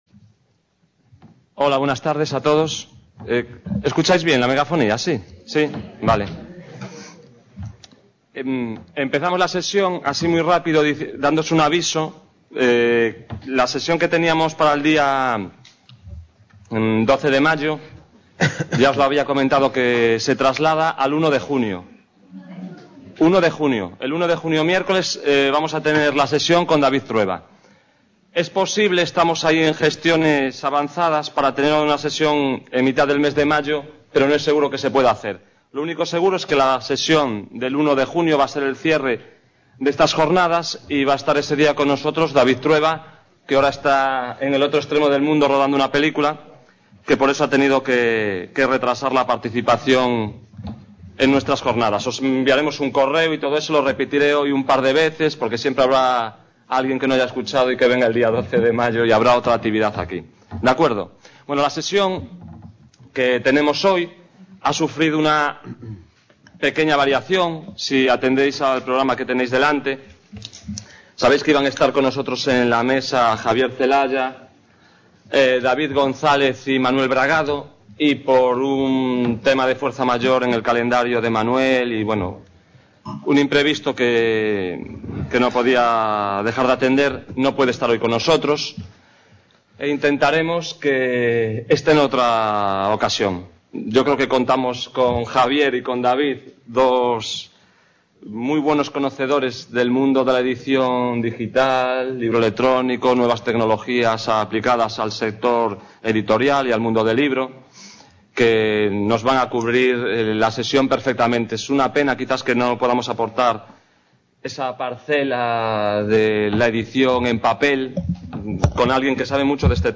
Los futuros del libro: el libro en el laberinto digital Description Evento desde CA de Coruña: Fecha: 28 de Abril, 18:00 horas Salón de Actos de la Uned.
| Red: UNED | Centro: UNED | Asig: Reunion, debate, coloquio...